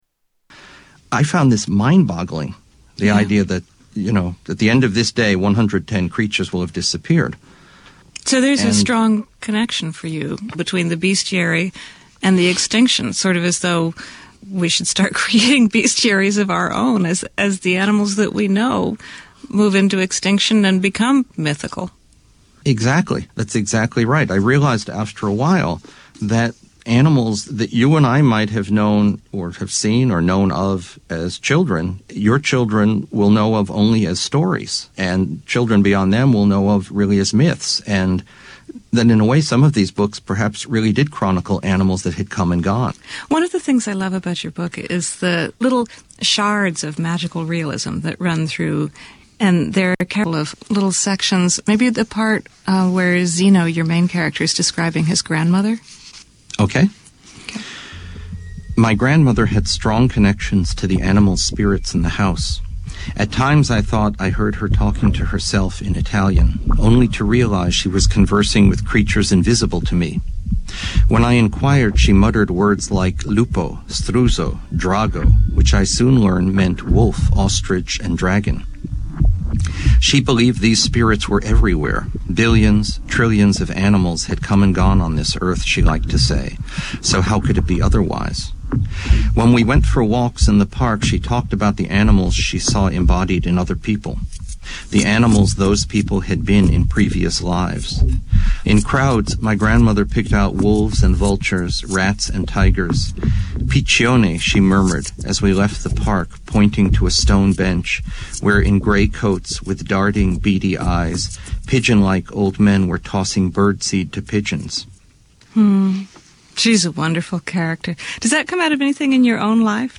Interview with author / researcher